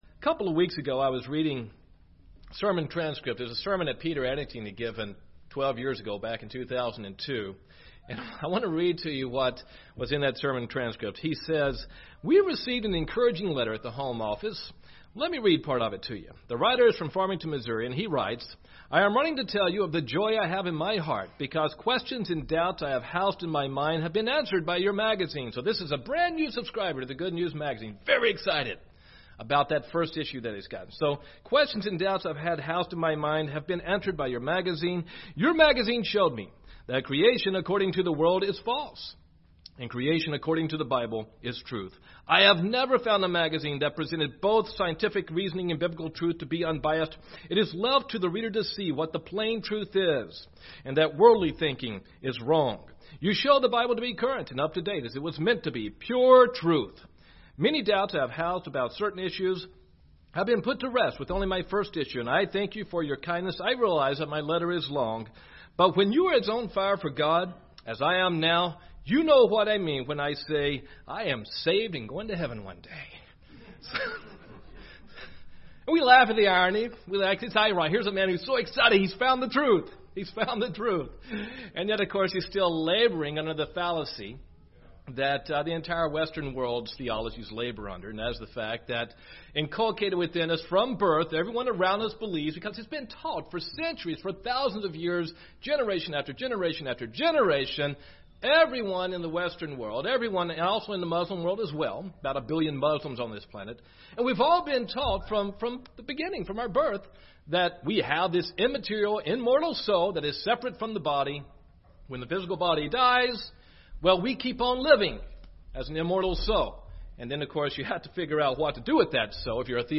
For thousands of years, people throughout the world have assumed that we are created with immortal souls that will live forever following our physical deaths. In this sermon, we'll take a look at where the doctrine of an immortal soul originated, and learn what the Bible tells us really happens to our spirit after death.
Given in Birmingham, AL Gadsden, AL